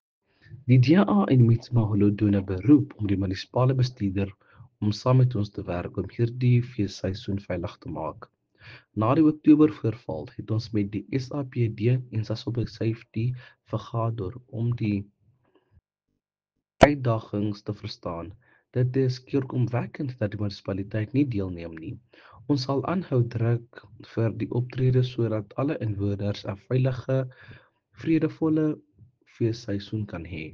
Afrikaans soundbites by Cllr Teboho Thulo and